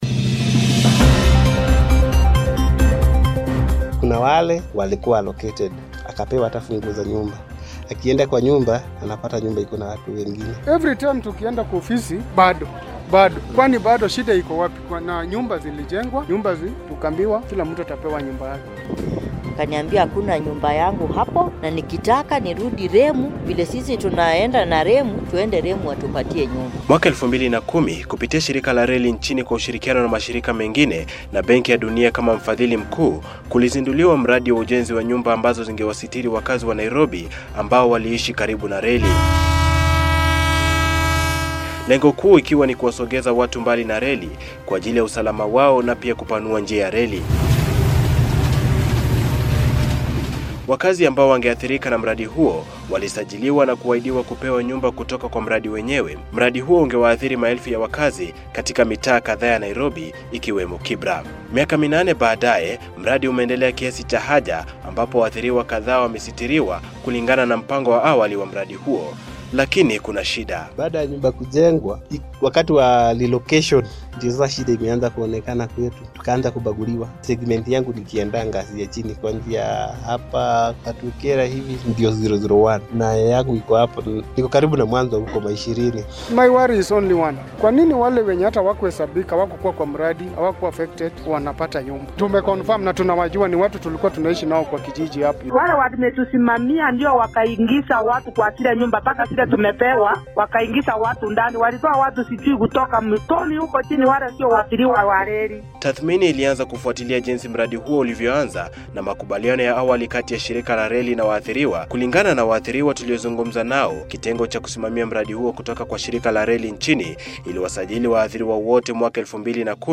Tathmini-ya-kizungumkuti-cha-mradi-wa-nyumba-za-reli-Kibera.-Feature-by-Pamoja-FM.mp3